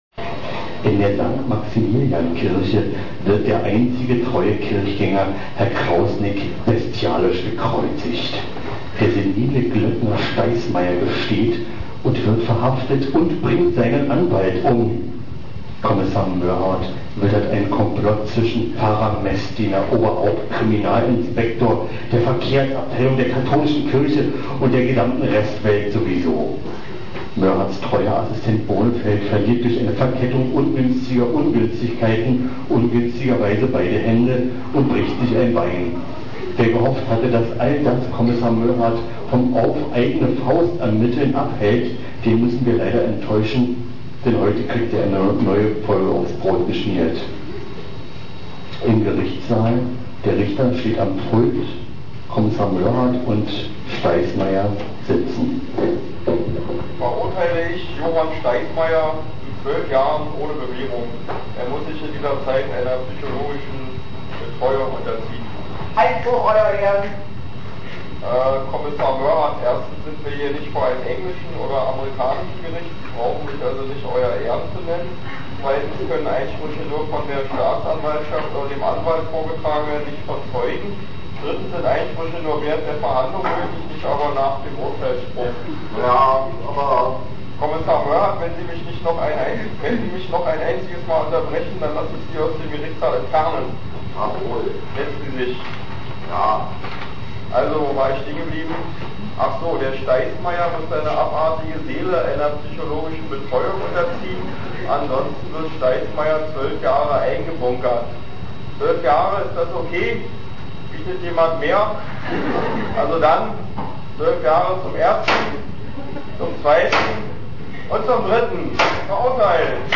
Verbeugung der Schauspieler